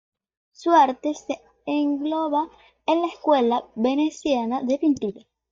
pin‧tu‧ra
Pronounced as (IPA)
/pinˈtuɾa/